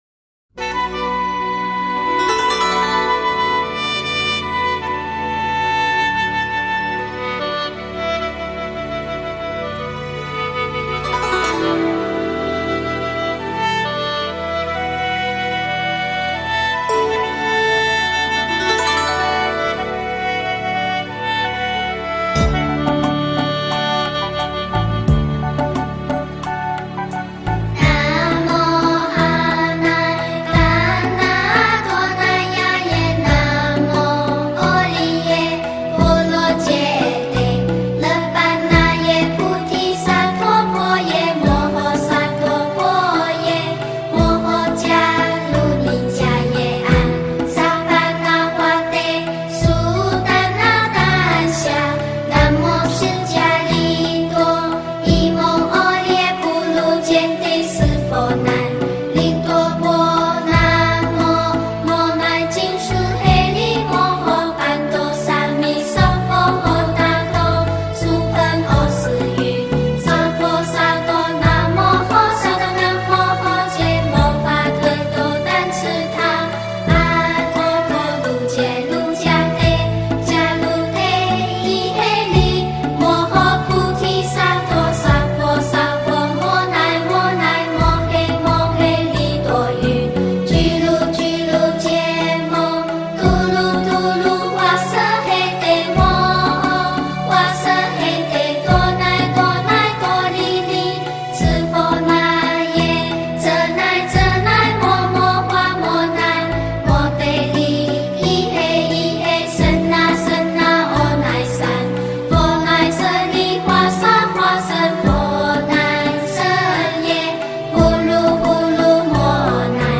大悲咒童声版
大悲咒童声版 诵经 大悲咒童声版--未知 点我： 标签: 佛音 诵经 佛教音乐 返回列表 上一篇： 心经 下一篇： 十一面观音根本咒 相关文章 心中的怙主--觉海梵音 心中的怙主--觉海梵音...